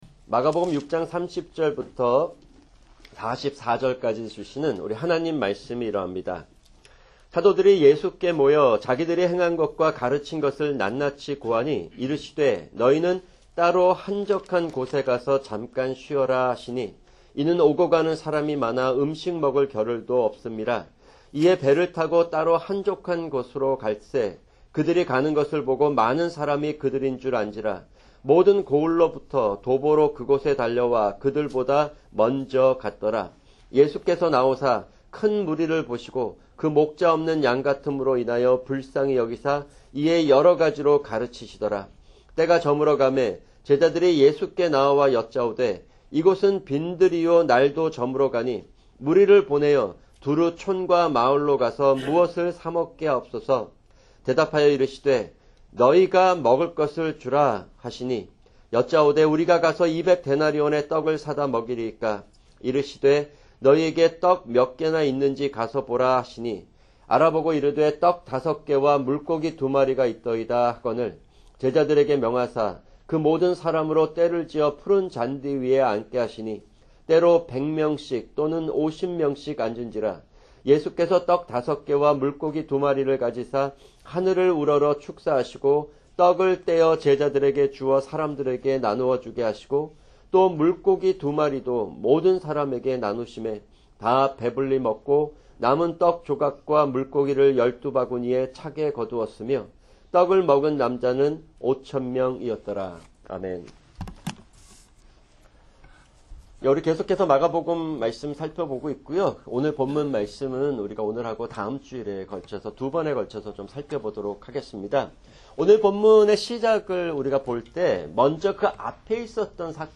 [주일 설교] 사무엘상 21:10-22:2